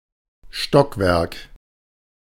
Ääntäminen
Synonyymit verdieping Ääntäminen Haettu sana löytyi näillä lähdekielillä: hollanti Käännös Ääninäyte 1.